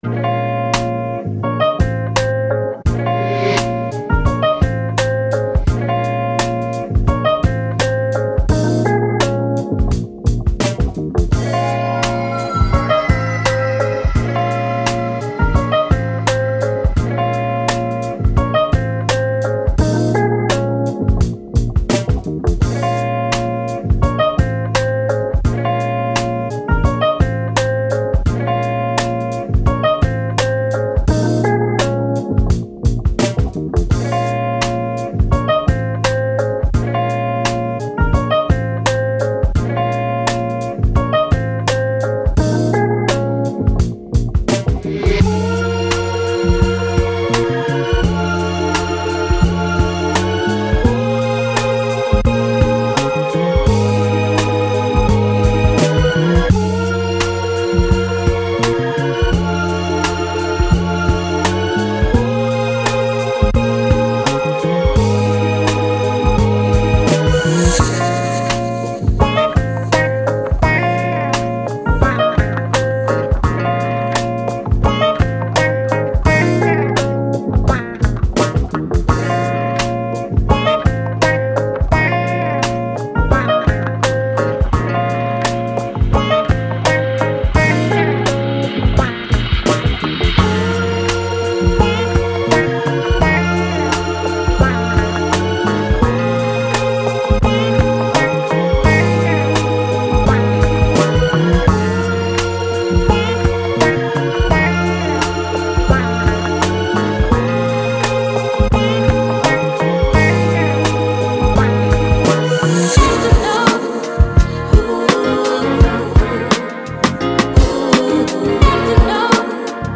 Hip Hop, Lofi, Chill, Vintage